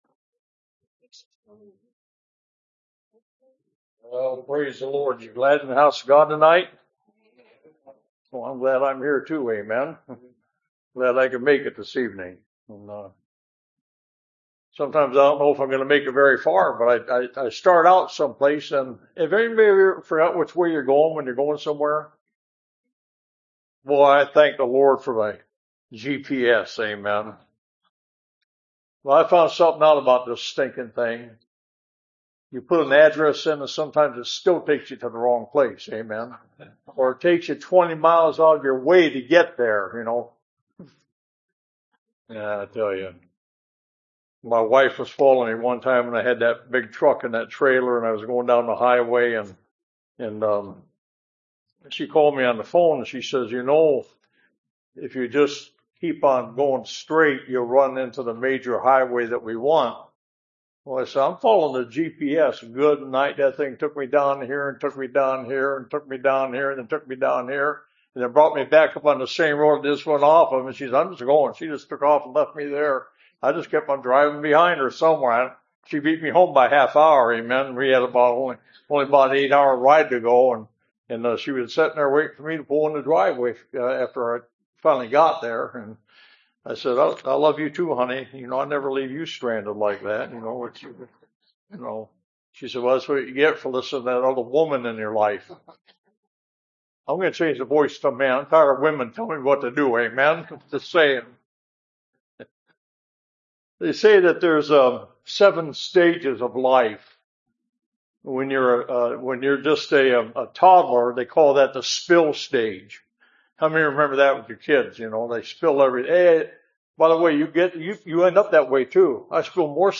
Revival Preachings Service Type: Revival